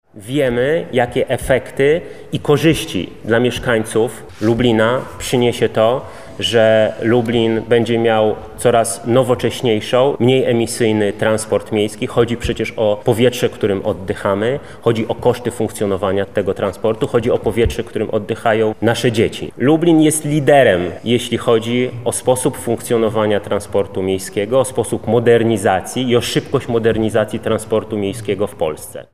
– mówi Michał Krawczyk, poseł na Sejm Rzeczypospolitej Polskiej.